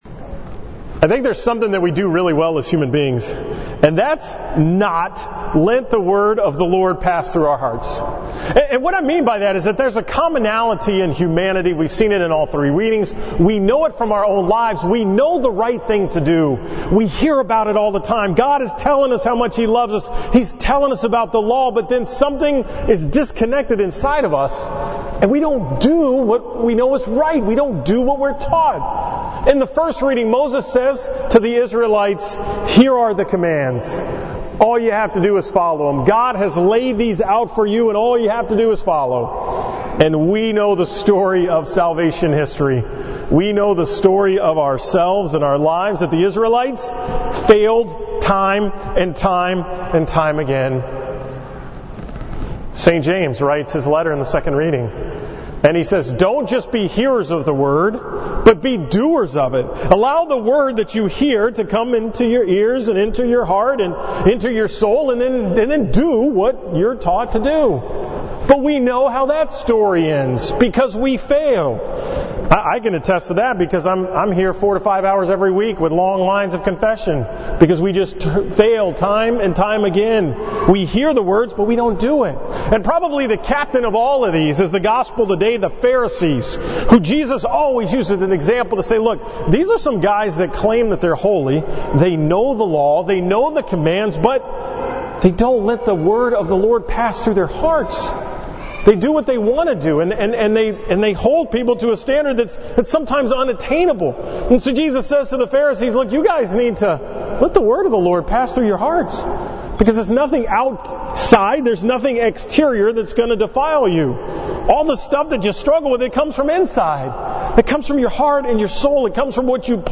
From the 5:30 pm Mass at St. Martha's on September 2nd, 2018